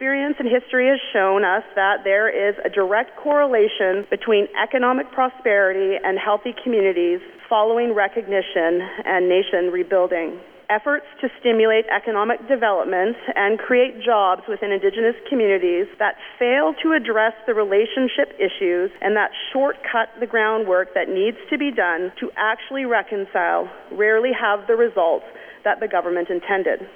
Political figures gathered together recently at the B-C Cabinet and First Nations Leaders Gathering.
Minister of Justice Jody Wilson-Raybould says an important issue affecting indigenous people is poverty…